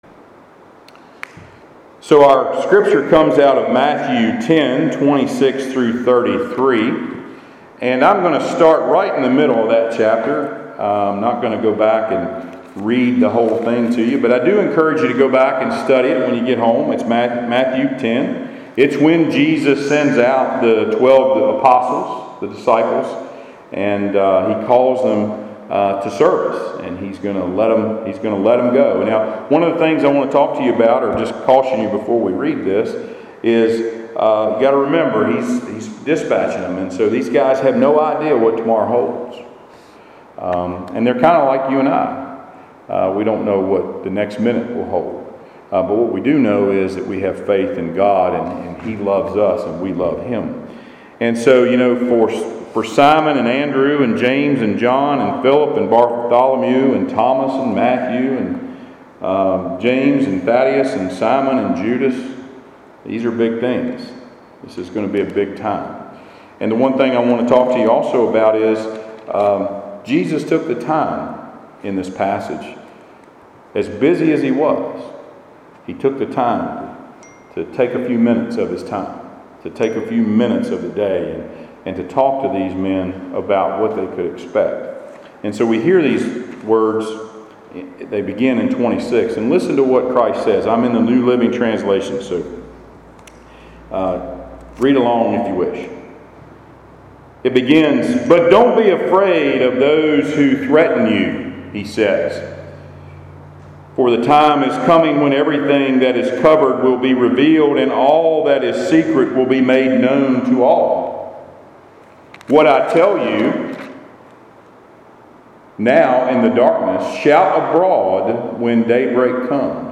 Passage: Matthew 10:26-33 Service Type: Sunday Worship